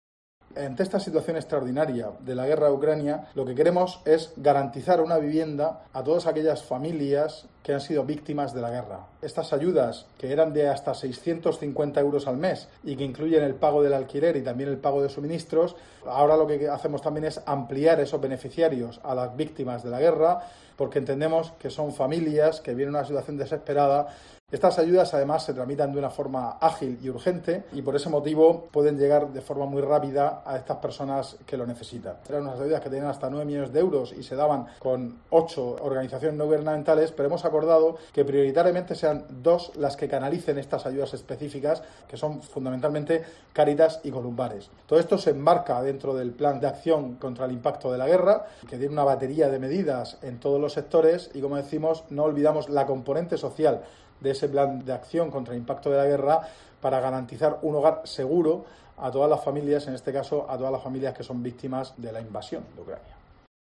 José Ramón Díez de Revenga, consejero de Fomento e Infraestructuras